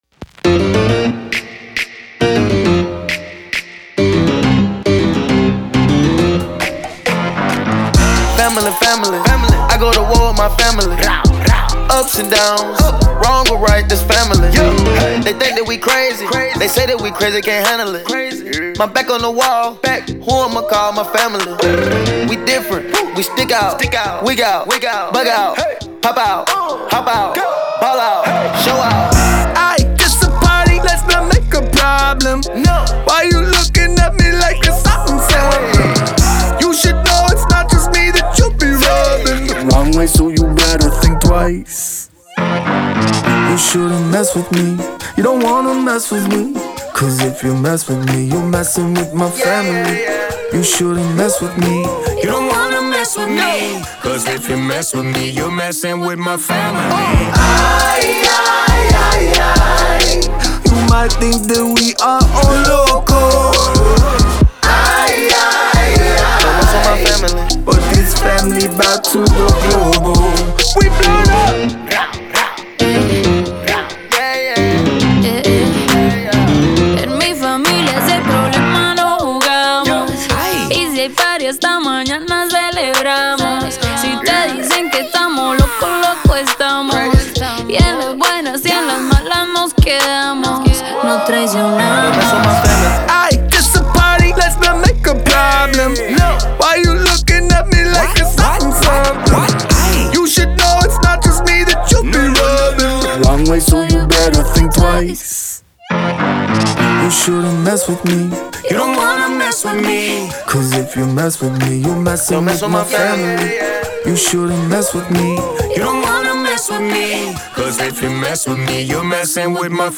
это энергичная хип-хоп композиция